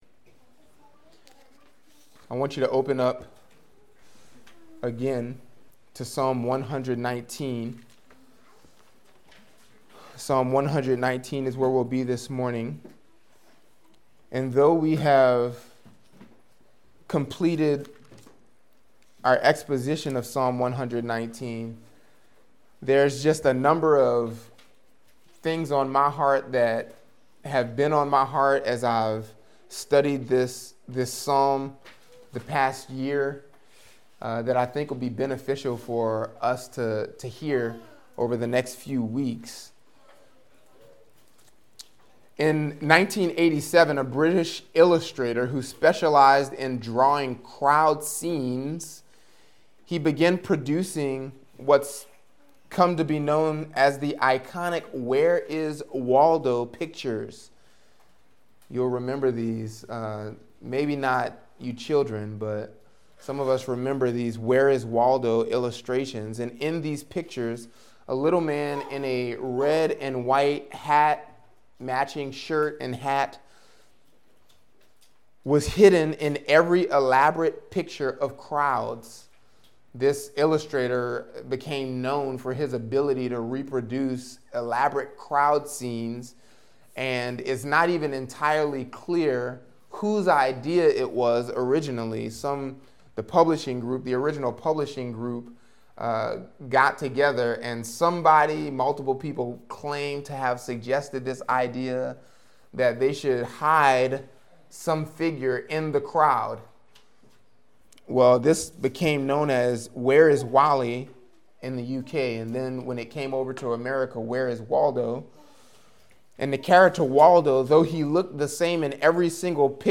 Podcast (gbc-nola-sermons): Play in new window | Download